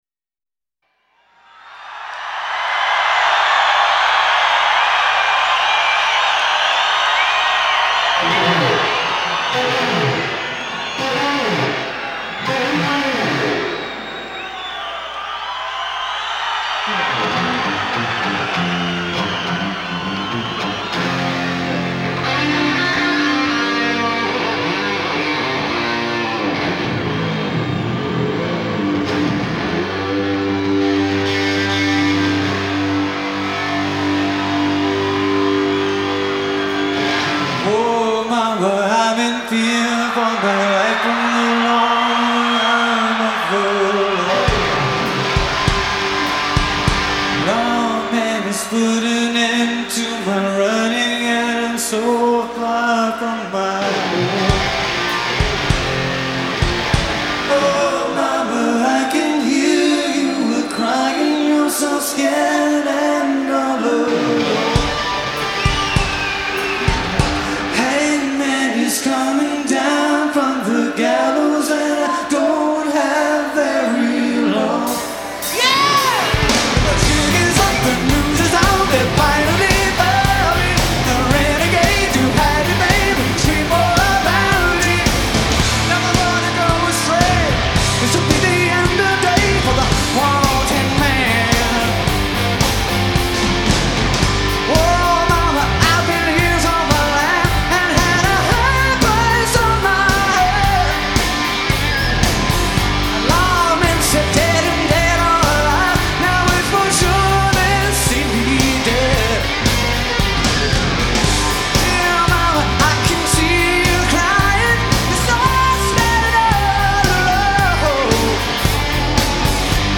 live in Denver June 20, 1992